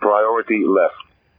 fly 9e3e11a93b Sidestick priority with indicators and sound ...
priority-left.wav